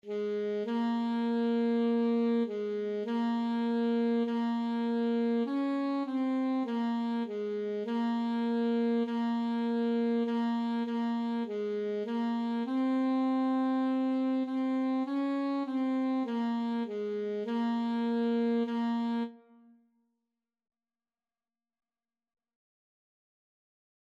Free Sheet music for Alto Saxophone
4/4 (View more 4/4 Music)
Ab4-Db5
Saxophone  (View more Beginners Saxophone Music)
Classical (View more Classical Saxophone Music)